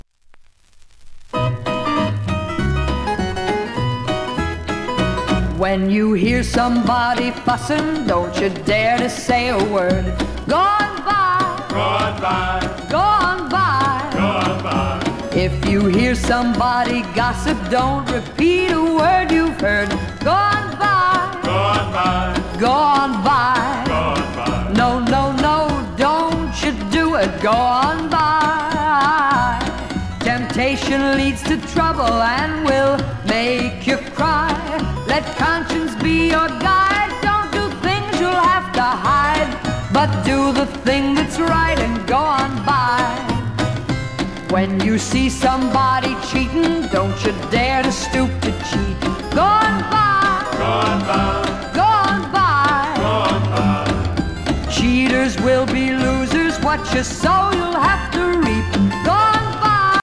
w/オーケストラ
1954年頃の録音